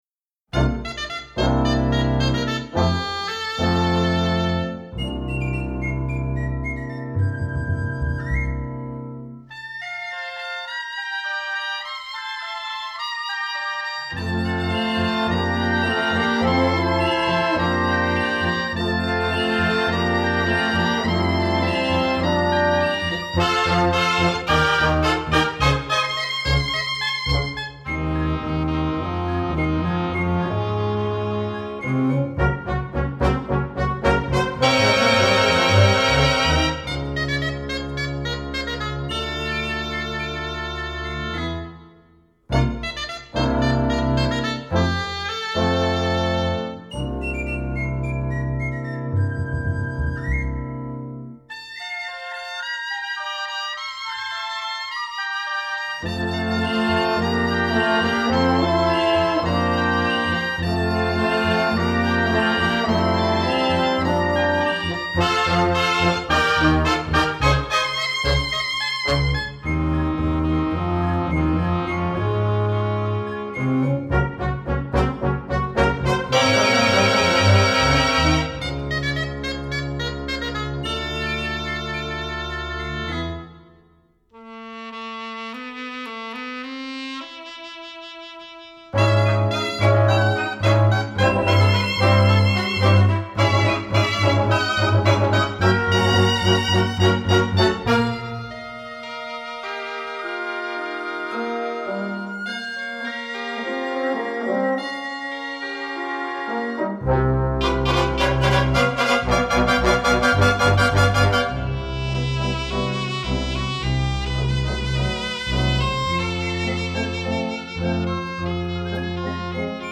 Exemple de musica contemporanya